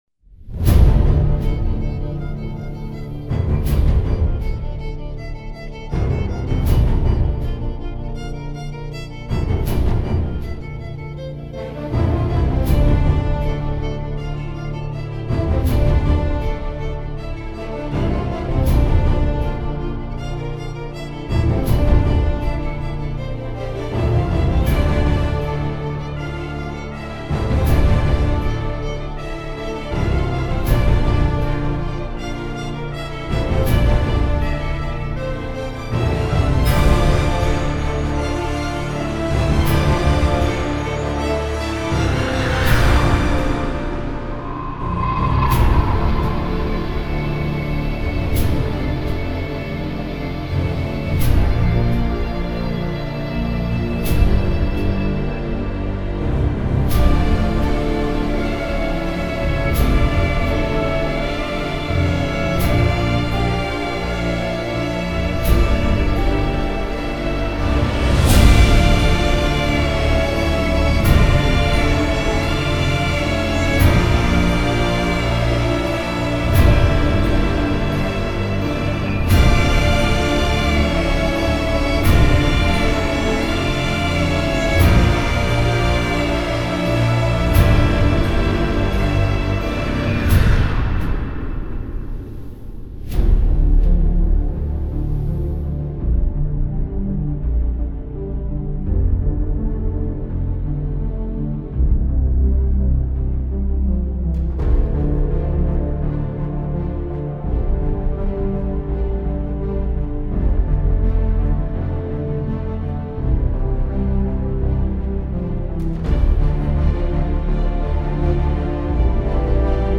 ژانر: بی کلام ، پاپ ، دنس الکترونیک